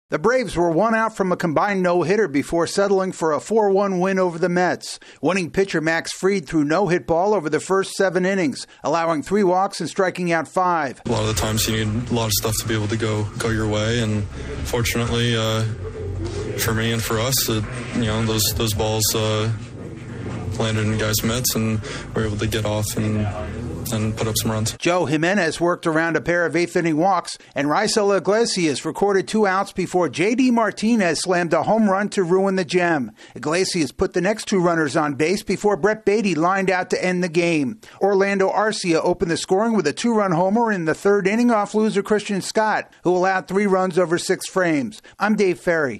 The Braves get a gem from their ace in beating the Mets for the second straight day. AP correspondent